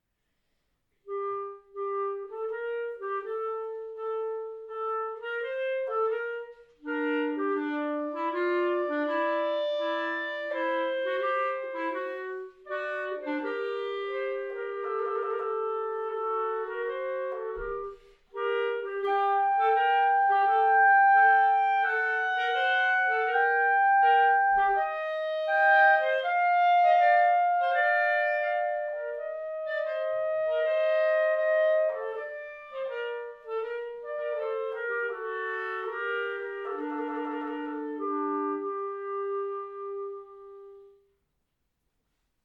Besetzung: 2 Klarinetten
Suiten für 2 Klarinetten